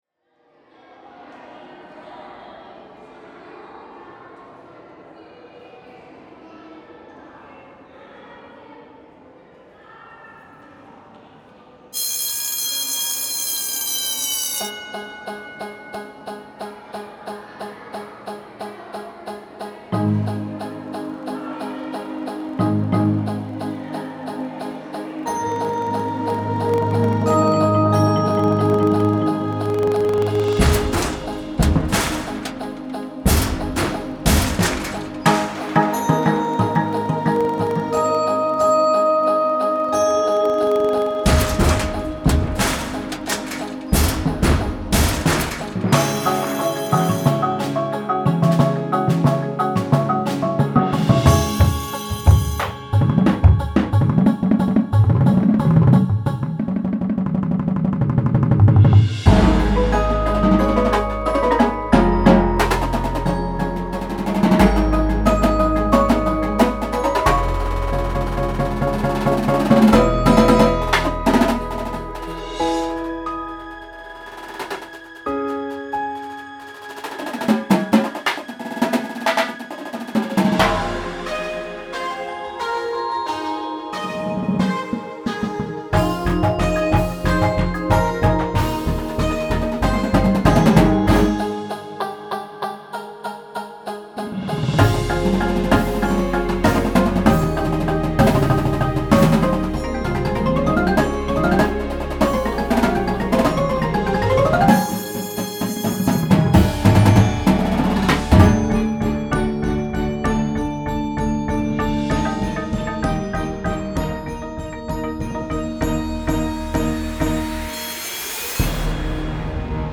Indoor Percussion Shows
• Snares
Front Ensemble
• 3/4 Vibes
• 2 Synths
• Timpani